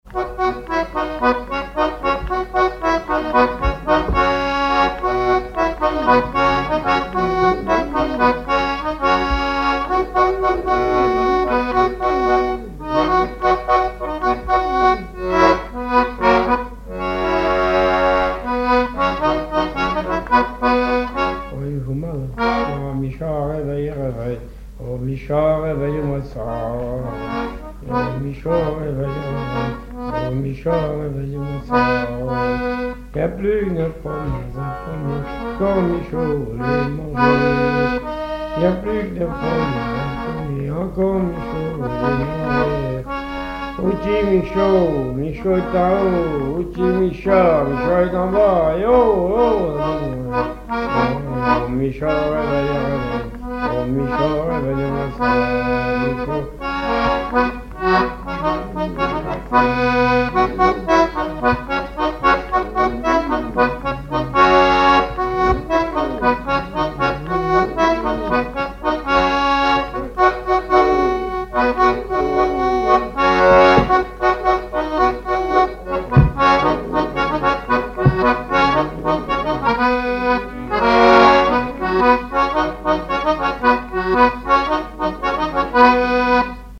danse : ronde : grand'danse
Genre énumérative
accordéon diatonique
Pièce musicale inédite